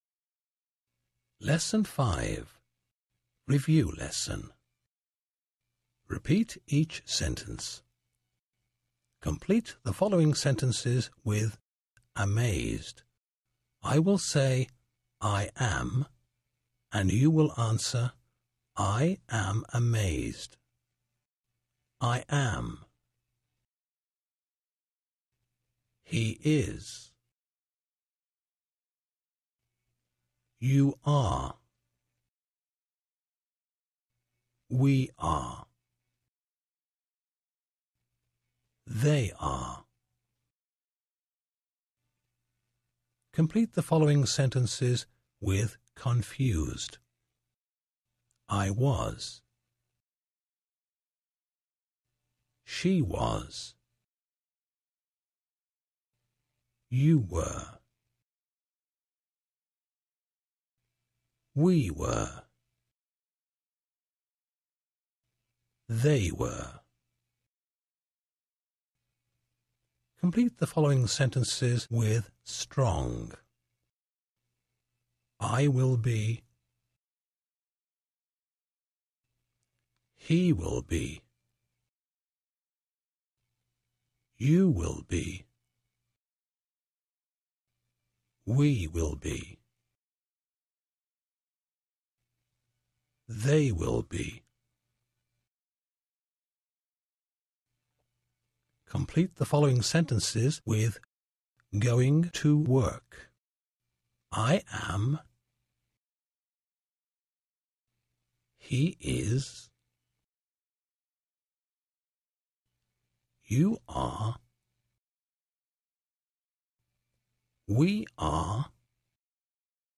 在线英语听力室免费英语课程培训-British Lesson 5ab的听力文件下载,免费英语课程培训,纯外教口语,初级学习-在线英语听力室